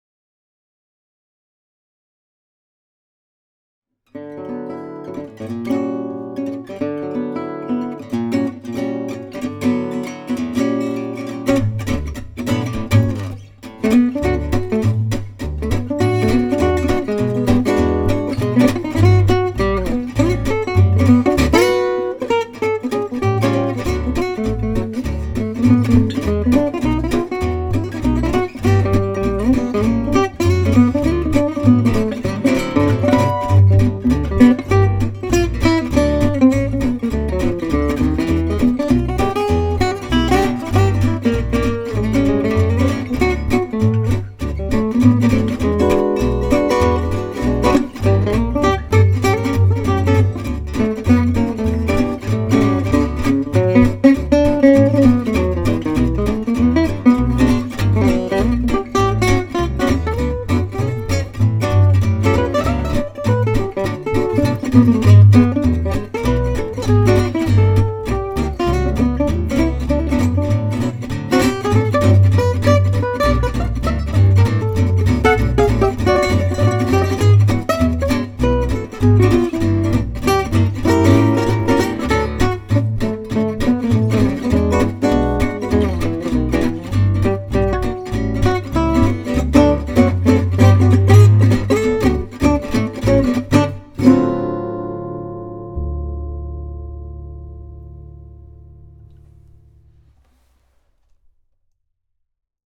Rhythm and lead on the updated Gallato, bass on my old EKO fretless.
It's playing nicely - loud and dry, and with a good woody bark!